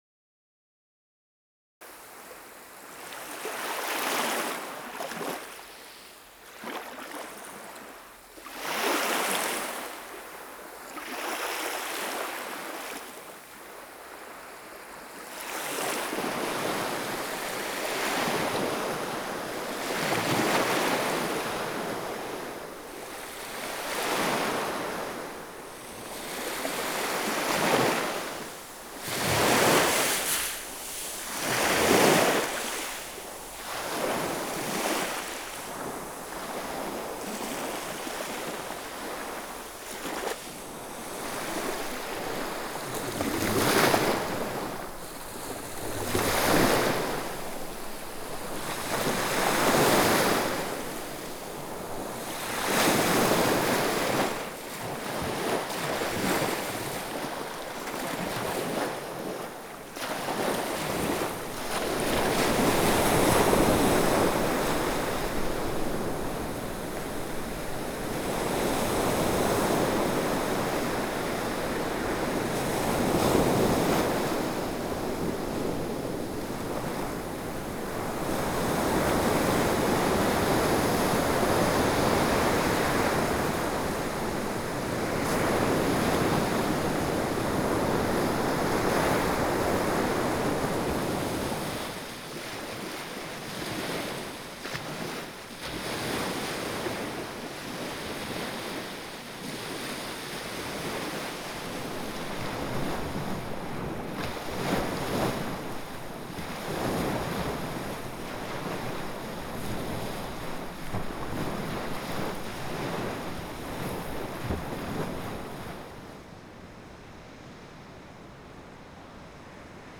AMBISONIC WAVES PREVIEW B Format FuMa.wav